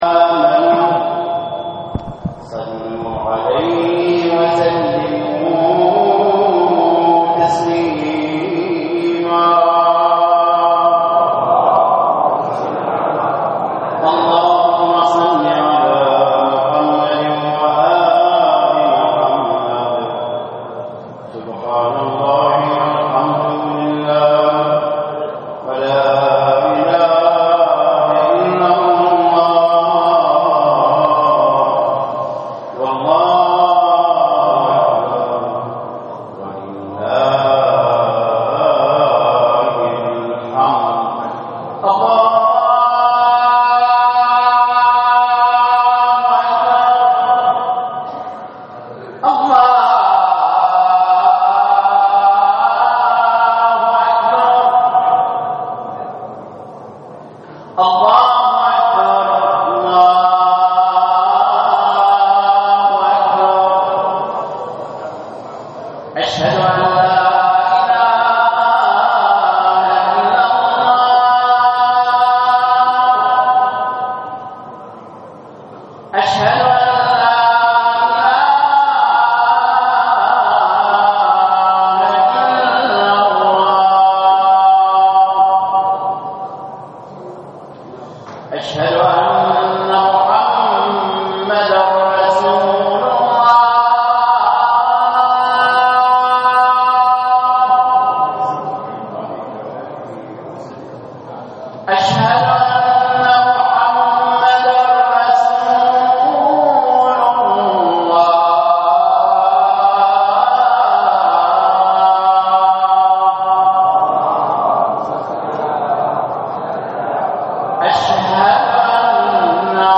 للاستماع الى خطبة الجمعة الرجاء اضغط هنا